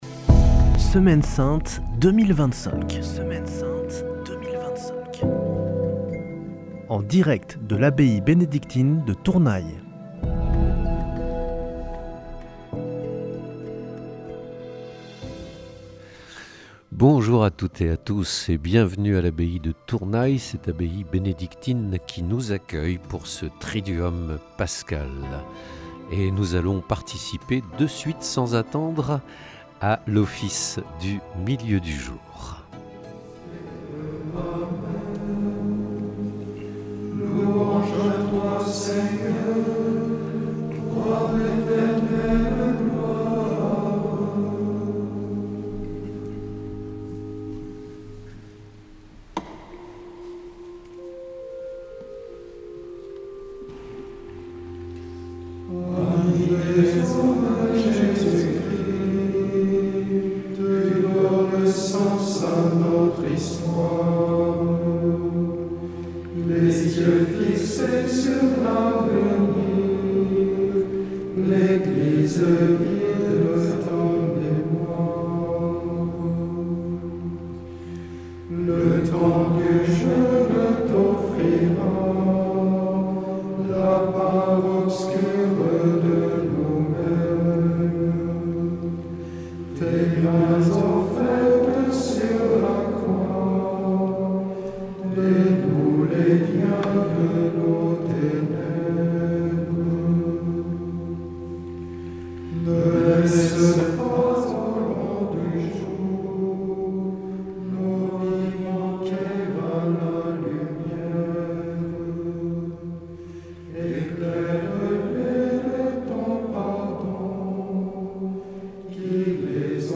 En direct de l'abbaye bénédictine de Tournay (Hautes-Pyrénées).